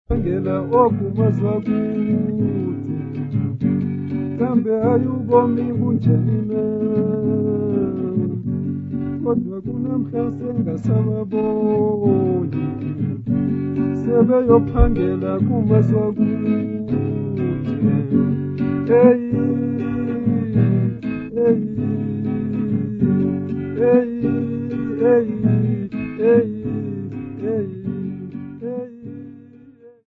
Folk music -- South Africa
Guitar
Africa South Africa Grahamstown f-sa
field recordings
Topical Xhosa urban song with guitar accompaniment